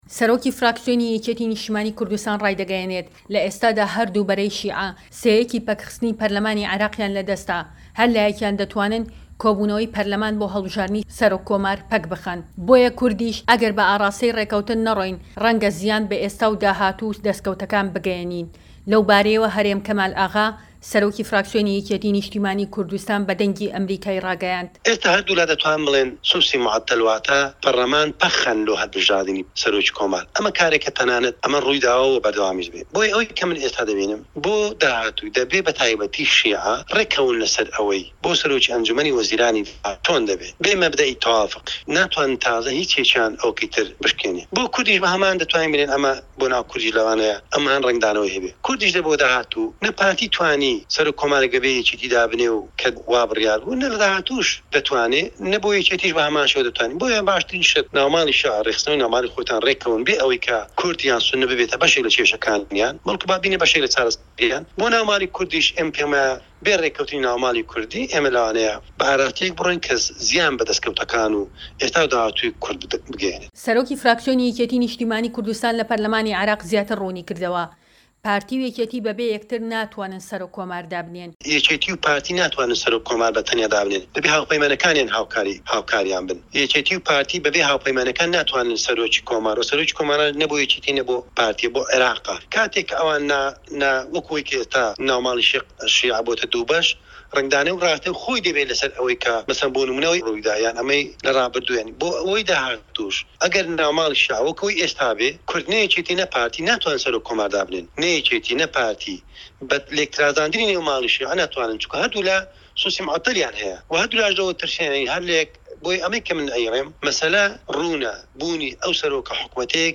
وتووێژ لەگەڵ هەرێم کەمال ئاغا